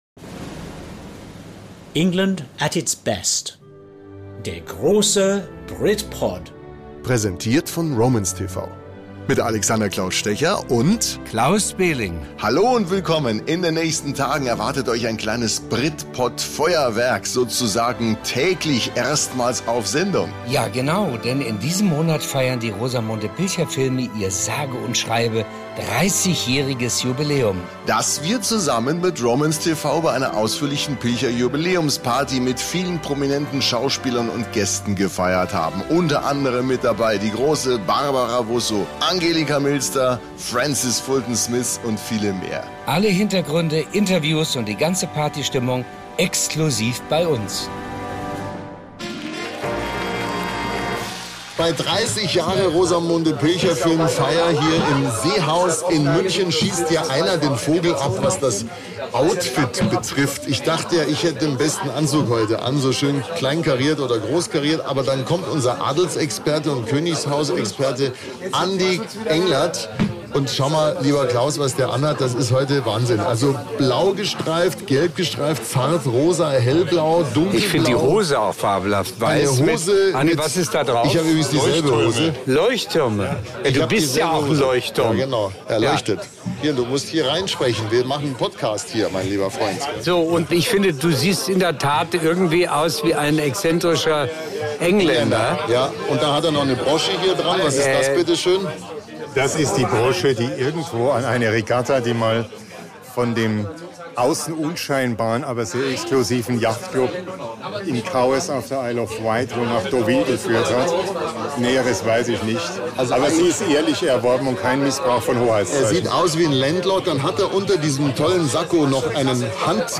Auf der großen Jubiläums-Party im
Englischen Garten in München